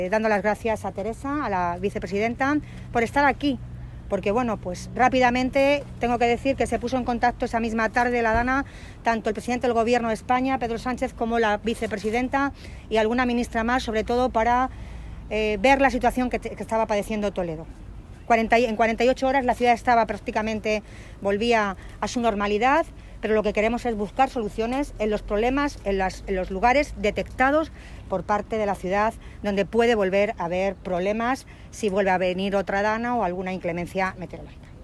En declaraciones a los medios en el barrio de Azucaica, Milagros Tolón ha agradecido la implicación, apoyo y compromiso del Gobierno de España para buscar entre todas las administraciones posibles soluciones para paliar los desperfectos y realizar las obras que son precisas acometer y así evitar desbordamientos como los acaecidos en los últimos días que podrían convertirse en habituales debido a las consecuencias del cambio climático.